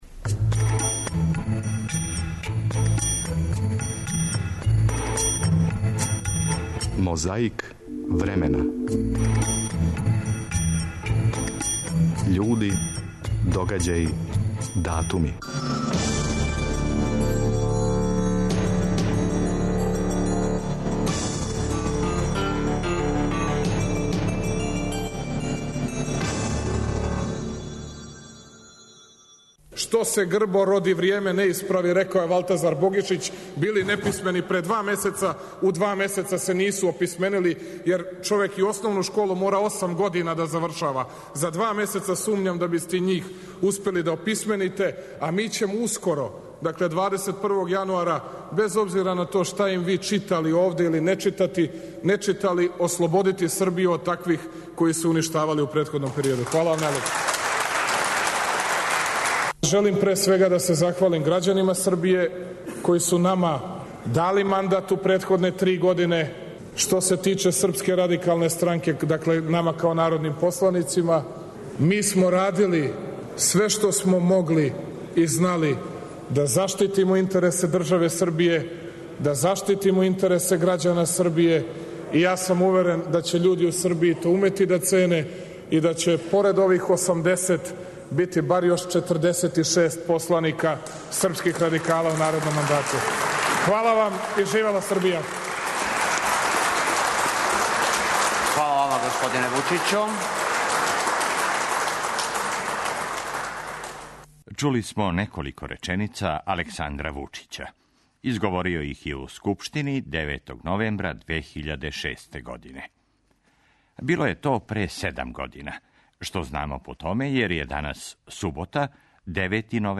Борбу против пилећег памћења ове суботе 'отвара' Александар Вучић. Подсећамо се шта је говорио у Скупштини 9. новембра 2006. године.
Зато и позајмљујемо из Тонског архива Радио Београда тонски запис који прича о томе како је пре пет година, Велимир Илић био и кандидат за председника Србије, па је као такав, 7. новембра 2003., гостовао у емисији Првог програма Радио Београда. 16. новембра 2000. обновљени су дипломатски односи СРЈ са Француском, Немачком, Великом Британијом и САД, прекинути за време агресије НАТО пакта.
Баш тога 16. новембра 2000., председник СРЈ, Војислав Коштуница, био је гост Првог програма Радио Београда...